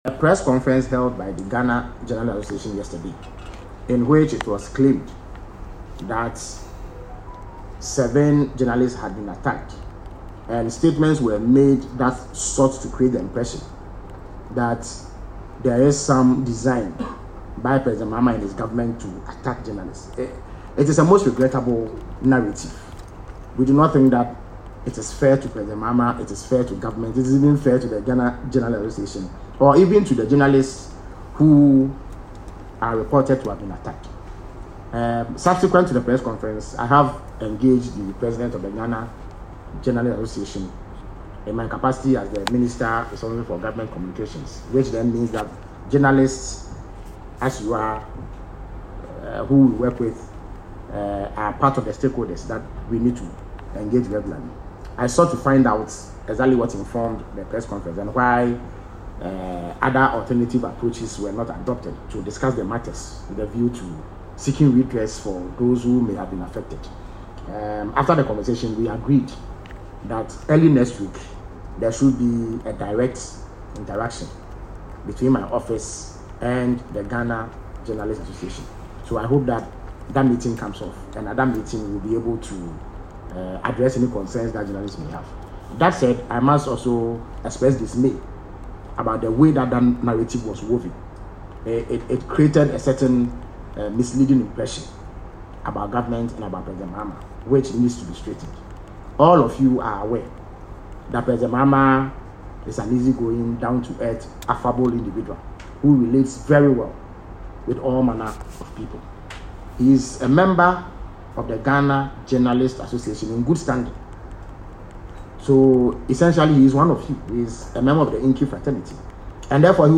Felix Kwakye Ofosu, the Communications Director at the Office of the President, in an address to the media on Wednesday, February 19, described the accusations as “regrettable” and misleading.